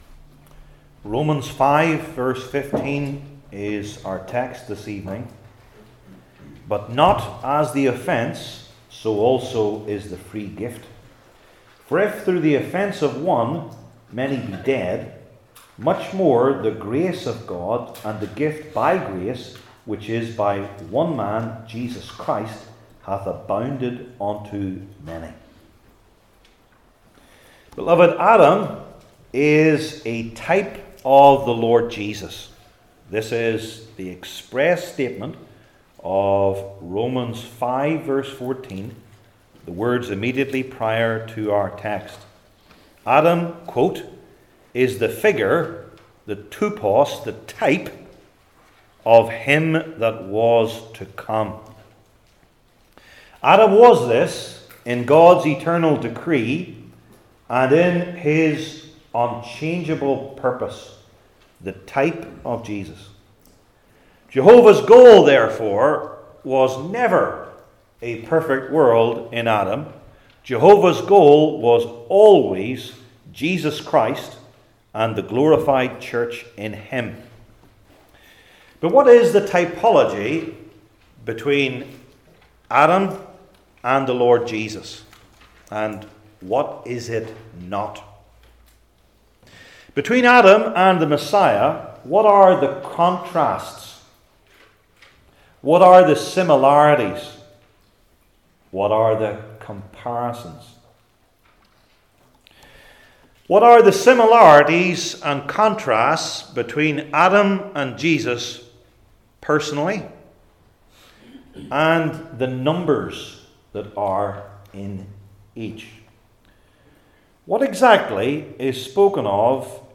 New Testament Individual Sermons I. Their Persons?